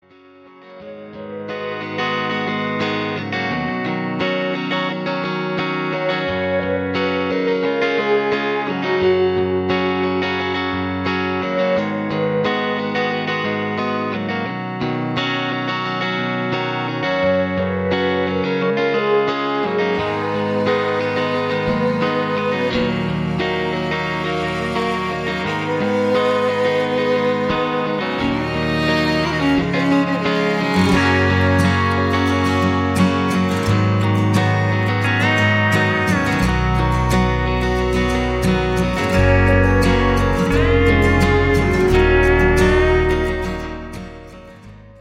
Midi Demo